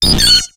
Cri de Tournegrin dans Pokémon X et Y.